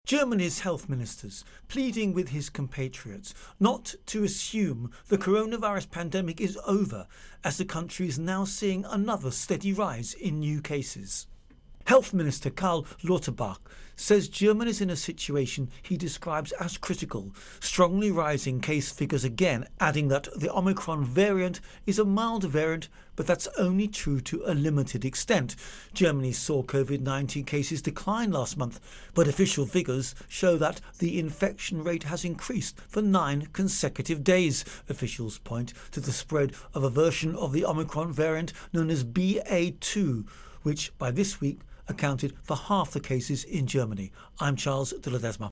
Virus Outbreak-Germany Intro and Voicer